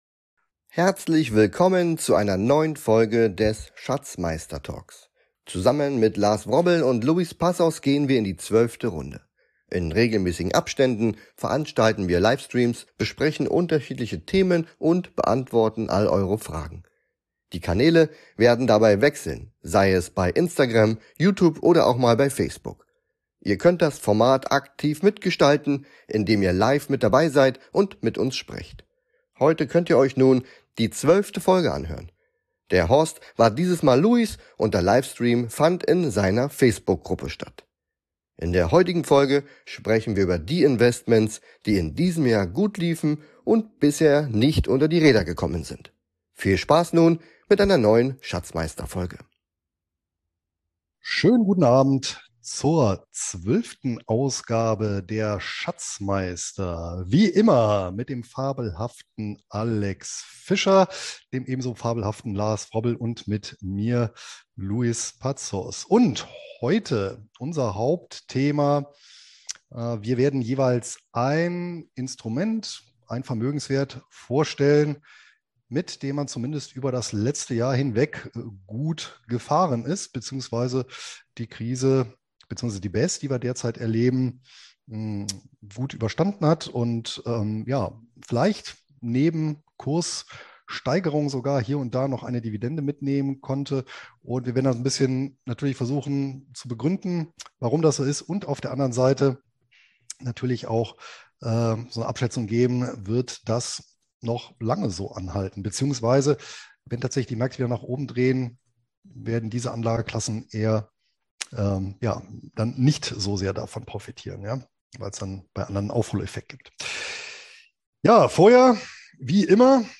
In regelmäßigen Abständen veranstalten wir Livestreams, besprechen unterschiedliche Themen und beantworten eure Fragen.